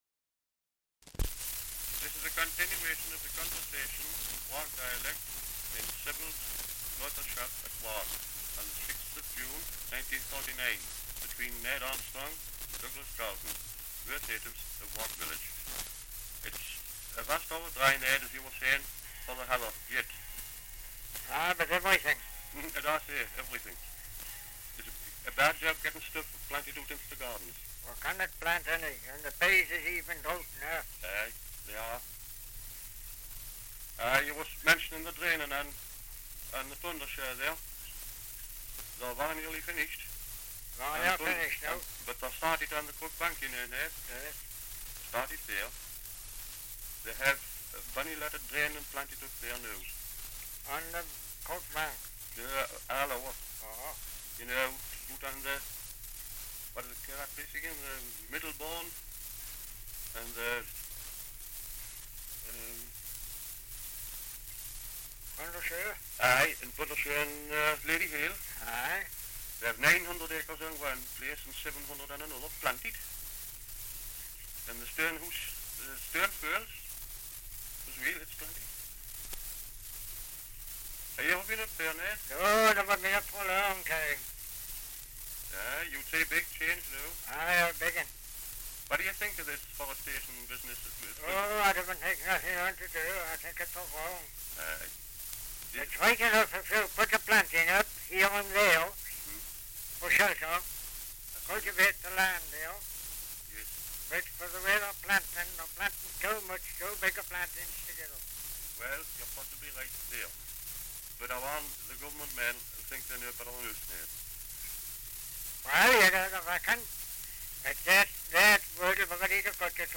2 - Dialect recording in Wark, Northumberland
78 r.p.m., cellulose nitrate on aluminium